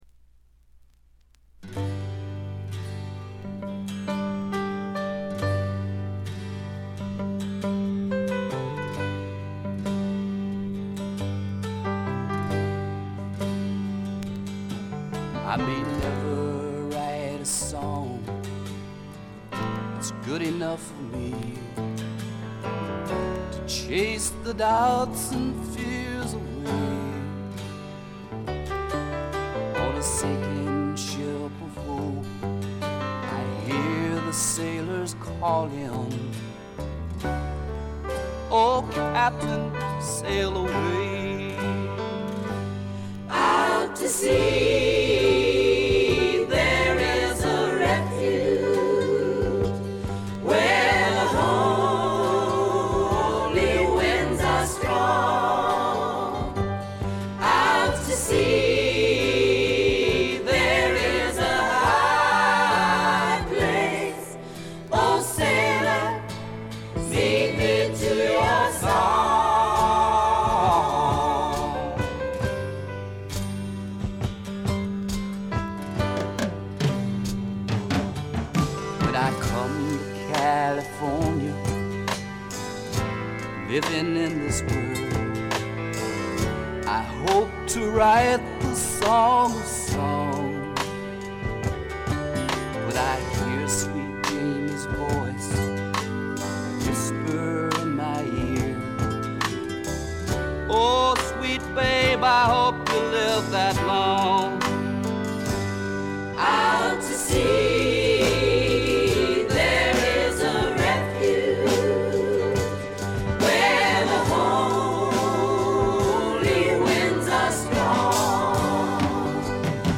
ところどころで軽微なチリプチ。散発的なプツ音少し。
試聴曲は現品からの取り込み音源です。